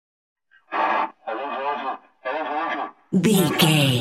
Spanish Policeman Car Megaphone
Sound Effects
urban
chaotic
ambience